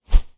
Close Combat Swing Sound 7.wav